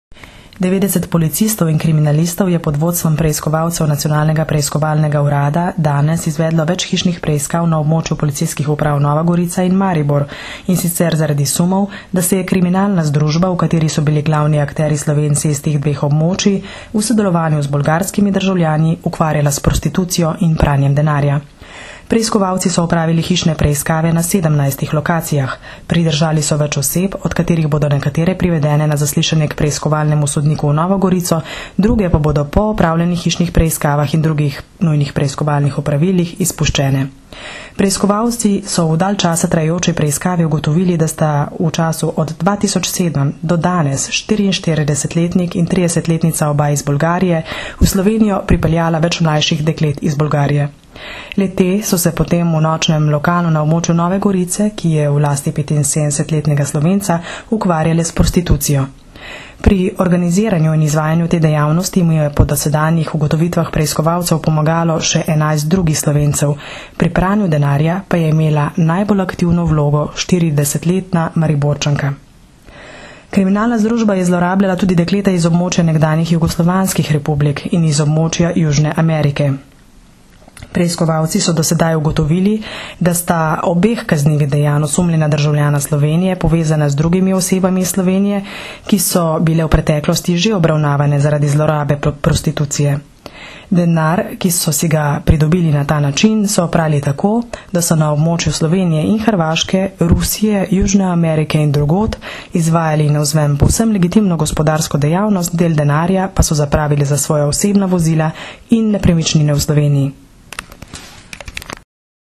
Zvočni posnetek izjave mag.